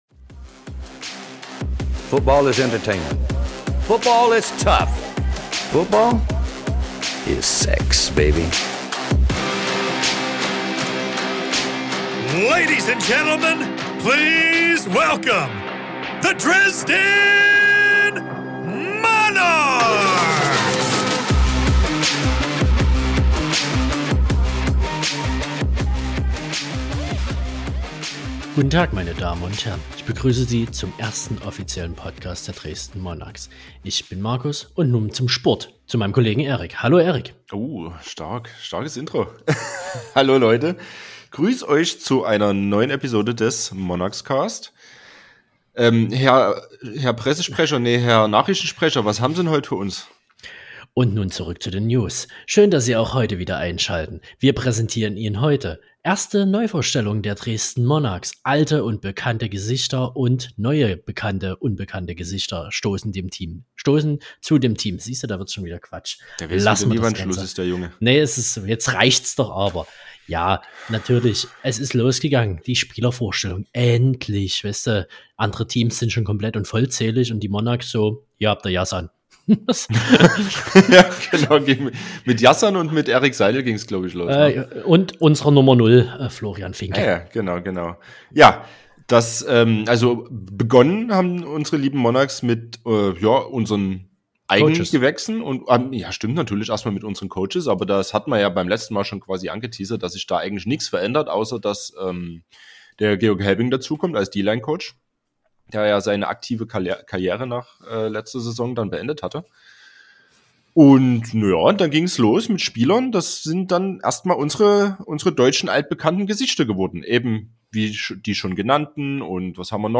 Offseason-Zeit ist Interview-Zeit.